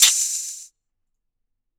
Closed Hats
West MetroHihat (20).wav